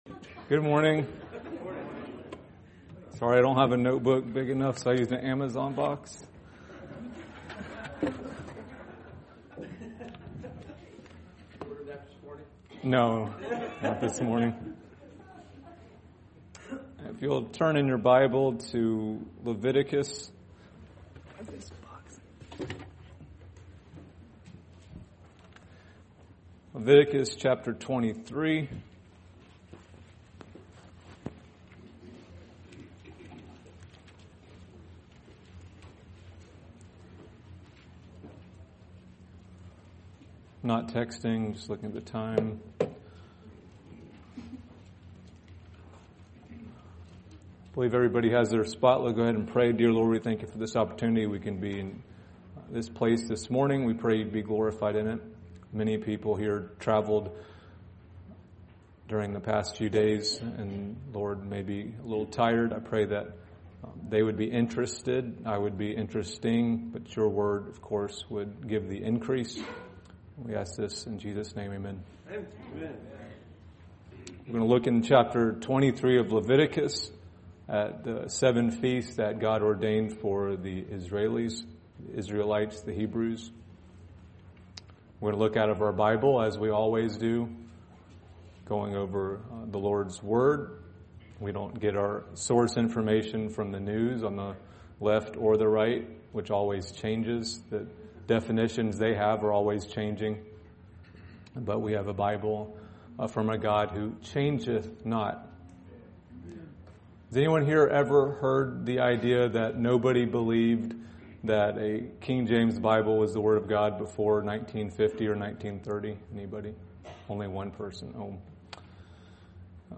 Leviticus 23 | Sunday School